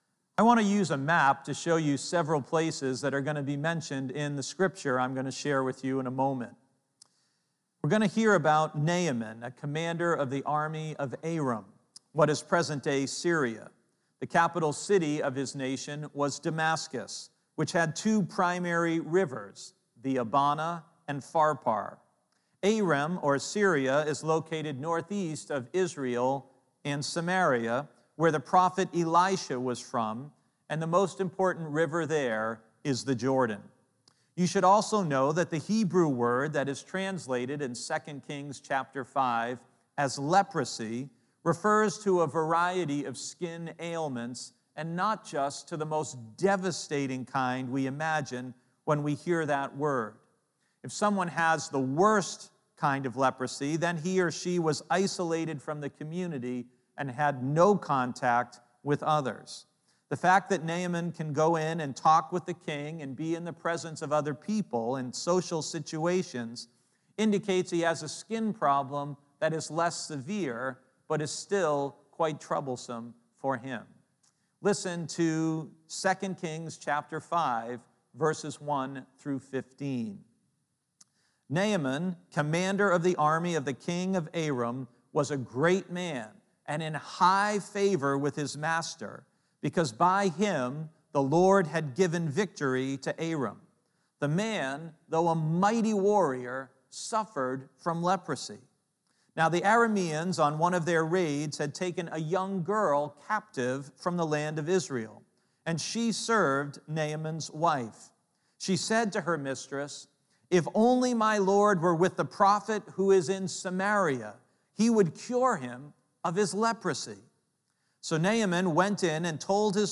The first video below is just the sermon.